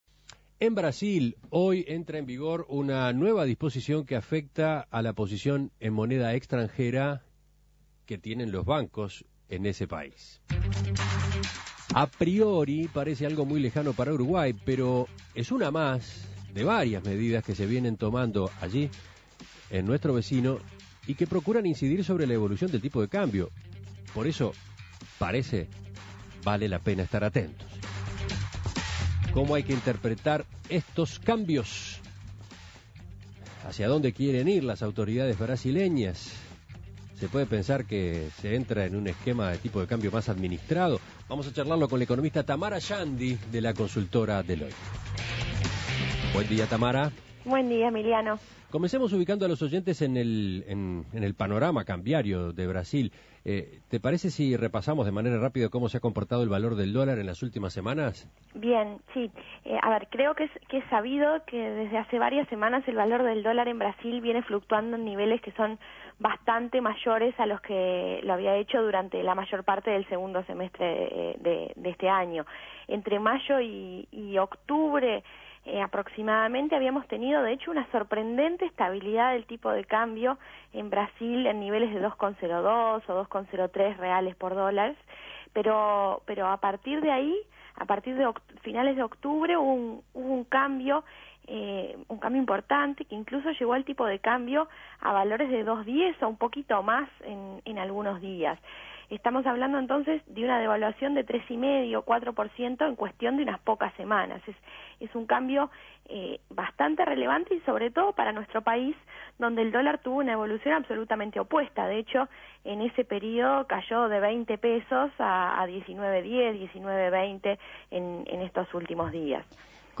Análisis Económico Nuevas medidas en brasil buscan incidir sobre la evolución del tipo de cambio.